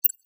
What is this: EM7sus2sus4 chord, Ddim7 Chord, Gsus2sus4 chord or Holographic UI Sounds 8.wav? Holographic UI Sounds 8.wav